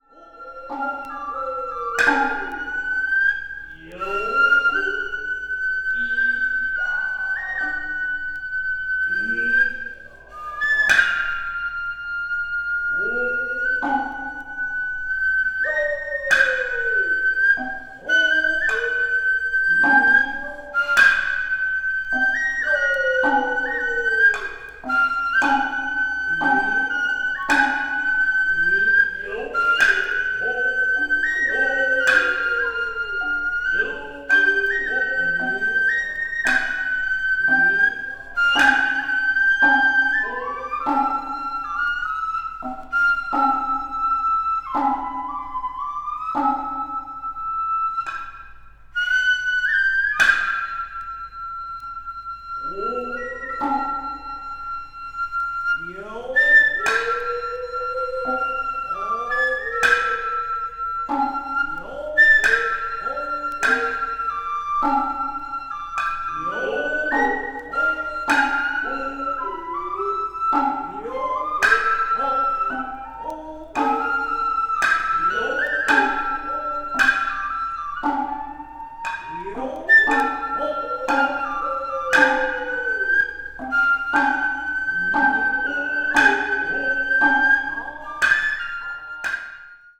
media : EX/EX,EX/EX(some slightly noises.)
the ensemble of flute, small drum, and large drum
The sound quality is exceptionally high.
east asia   ethnic music   japan   oriental   traditional